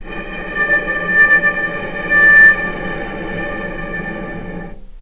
vc_sp-D5-pp.AIF